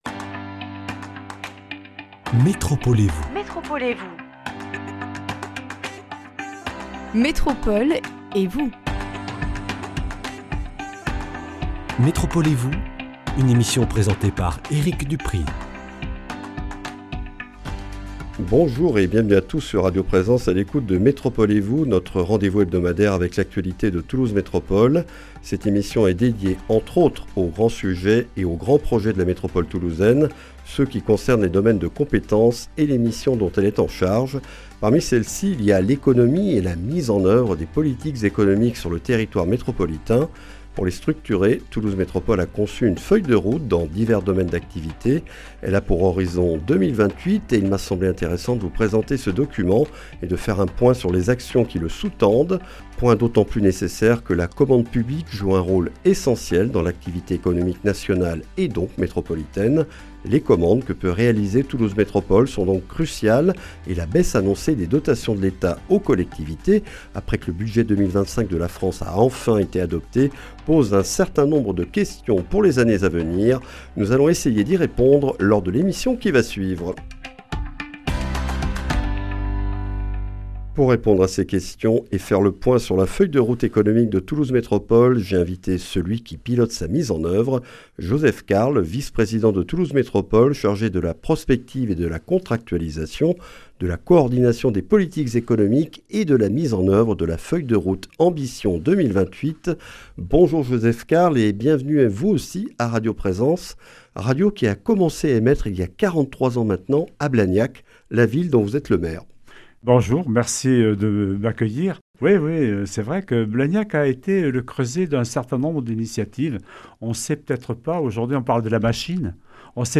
Dans cette émission, nous faisons le point sur Ambition 2028, la feuille de route économique de Toulouse Métropole, avec Joseph Carles, maire de Blagnac, vice-président de Toulouse Métropole en charge de la Prospective, de la Contractualisation, de la Coordination des politiques économiques et de la mise en oeuvre de la feuille de route Ambition 2028. Alors que l’État a annoncé la baisse de ses dotations aux collectivités, où en sont les 7 projets et 23 actions autour desquels ce document est structuré ?